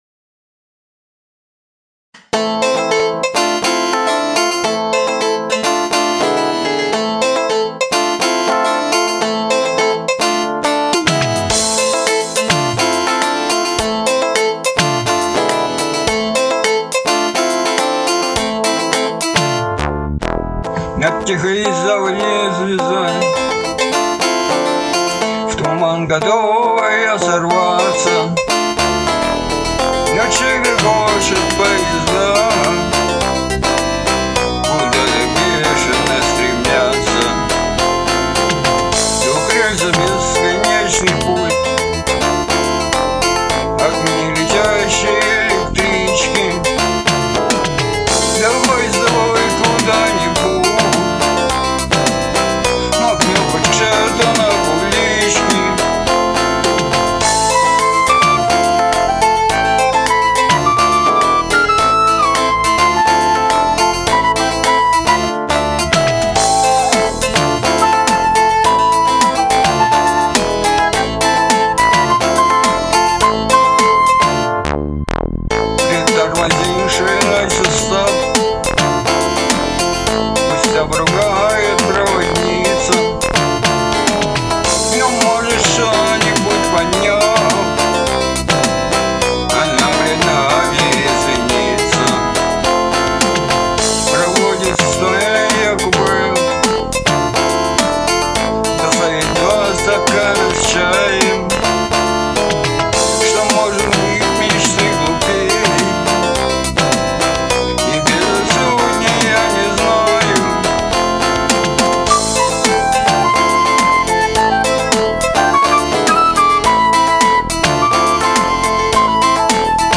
• Жанр: Поп